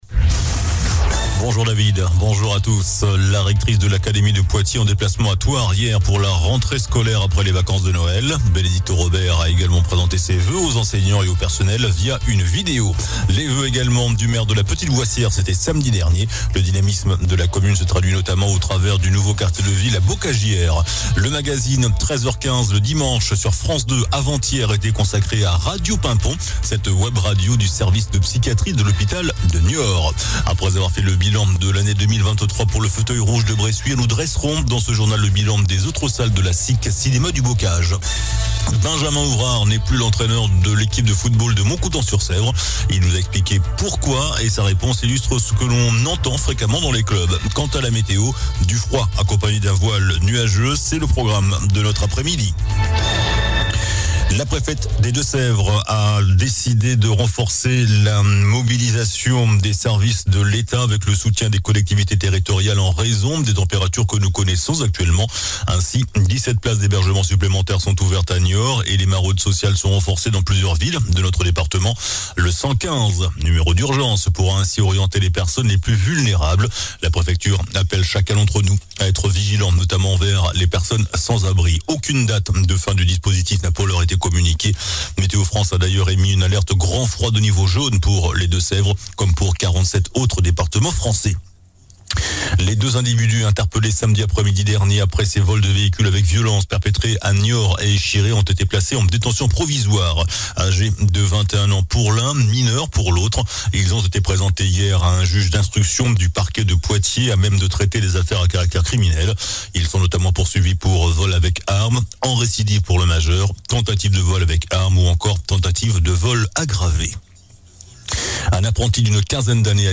JOURNAL DU MARDI 09 JANVIER ( MIDI )